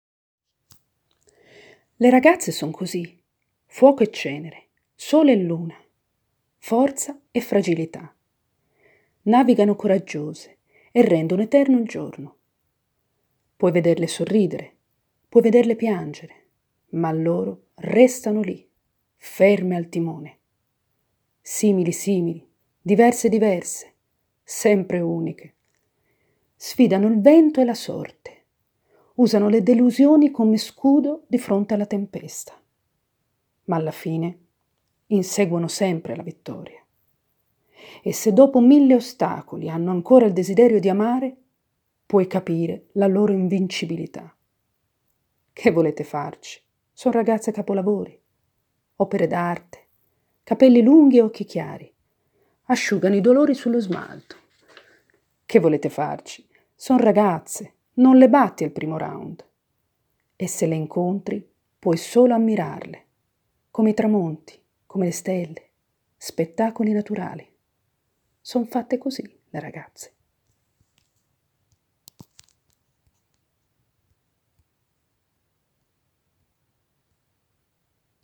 Le-ragazze-audiopoesia.mp3